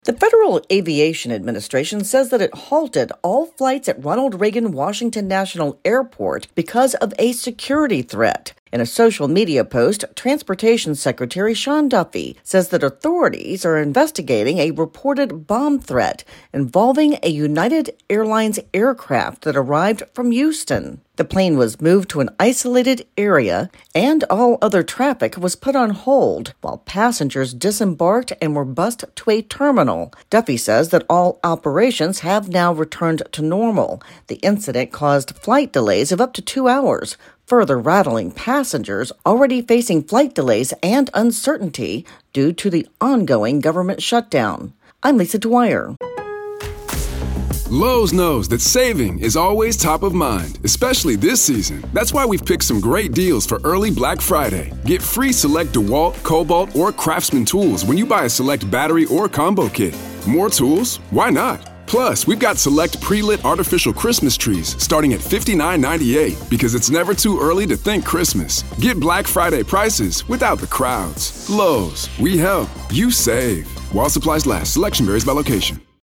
reports on a security scare at a Washington area airport.